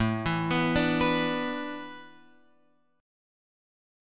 Asus2sus4 chord